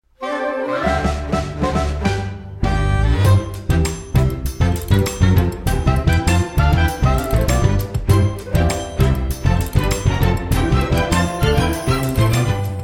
An arrangement